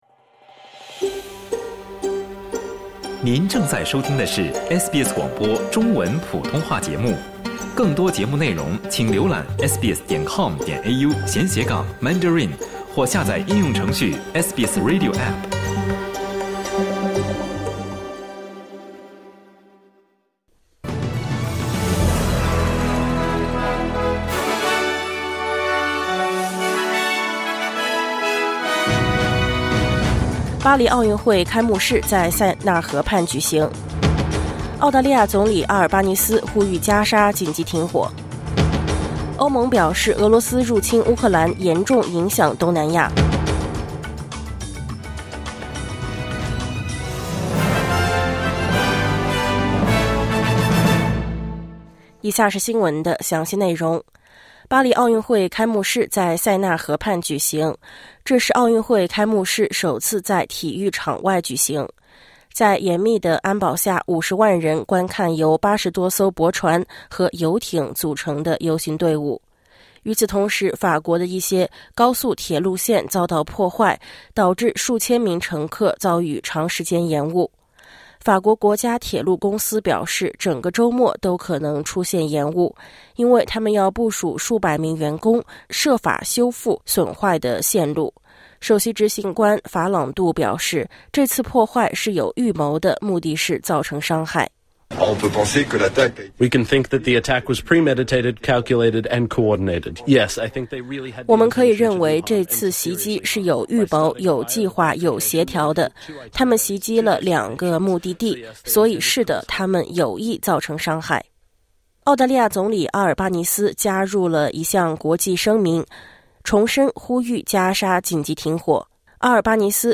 SBS早新闻（2024年7月27日）